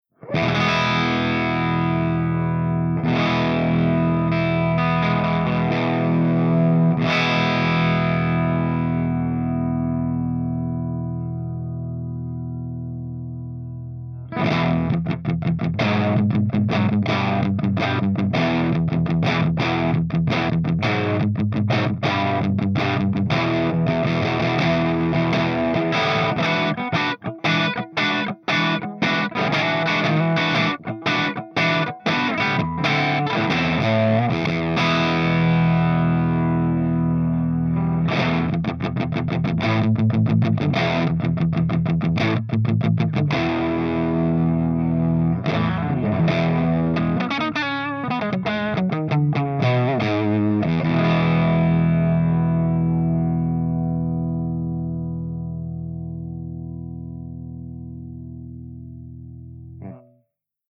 109_PLEXI_CH2EXTRADRIVE_GB_HB.mp3